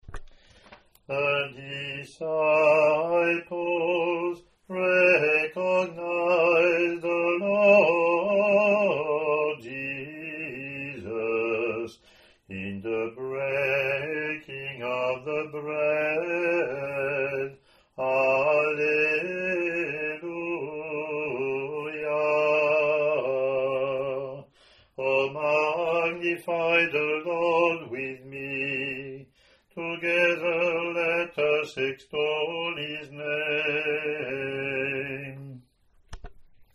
Click to hear Communion (
English antiphon – English verse , or Year A Latin antiphon + verses)